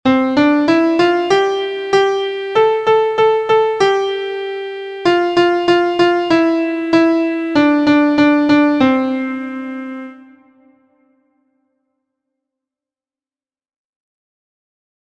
Stepping up and down the staff from Do to La.
• Origin: USA – Traditional
• Key: C Major
• Time: 2/4
• Pitches: beginners: Do Re Mi Fa So La